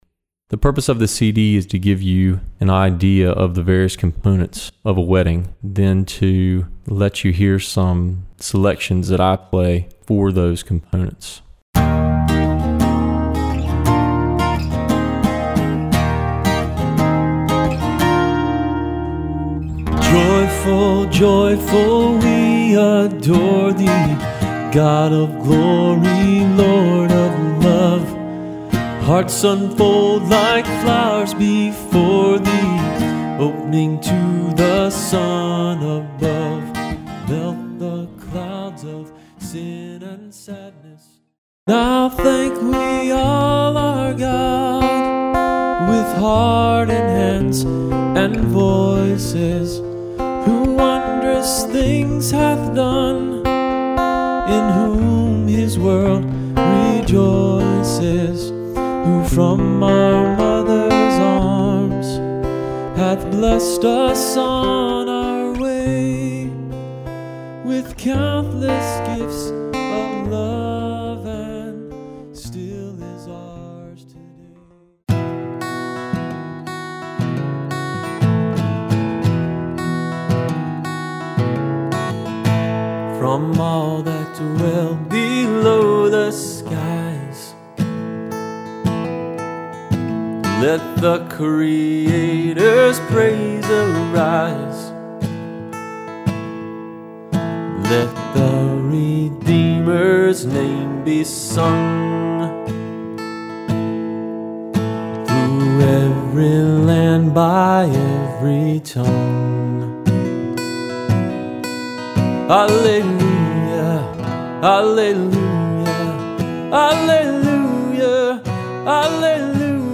I am a skilled guitar player and a decent vocalist. I’ve been performing for many years, particularly as a solo acoustic act.